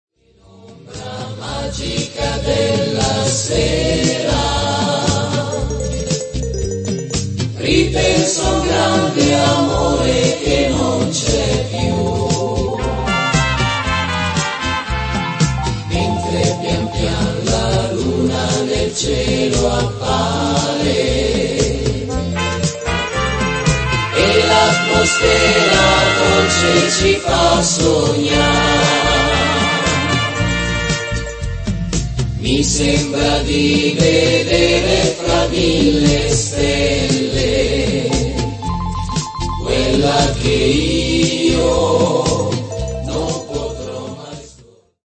beguine